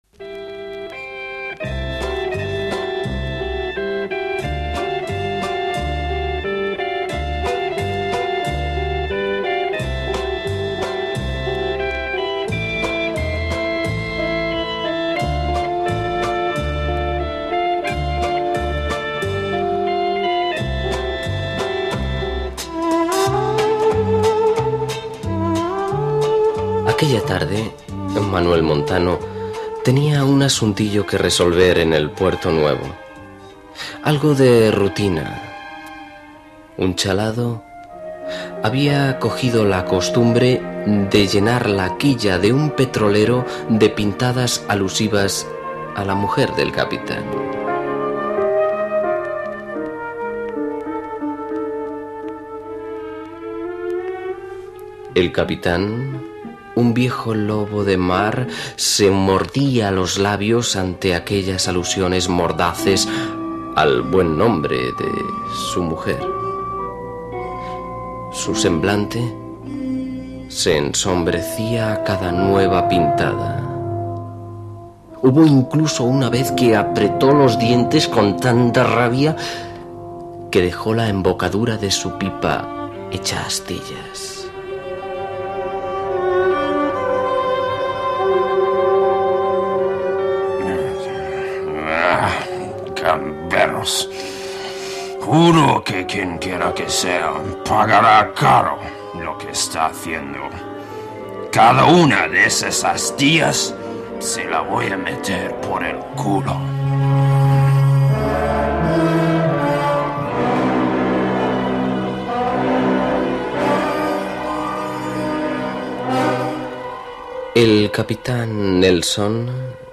Capítulo Graffitis, del serial 'El Manantial de la noche'